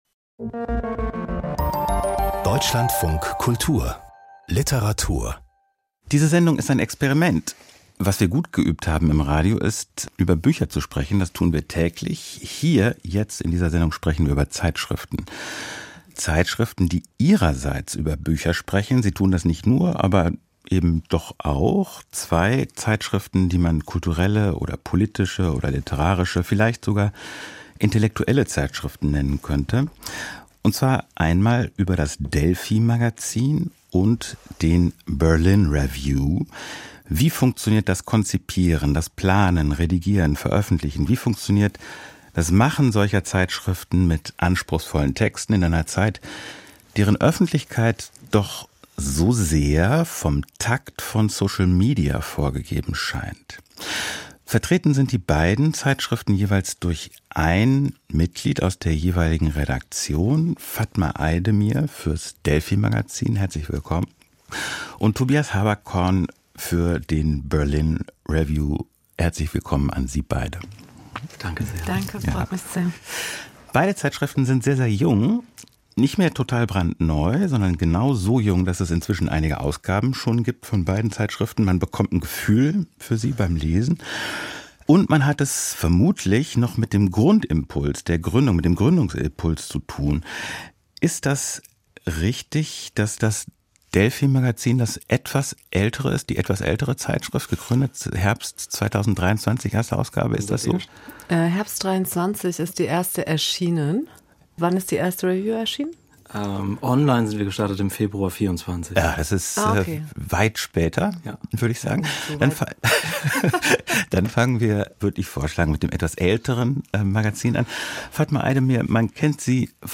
Literarische Features und Diskussionen.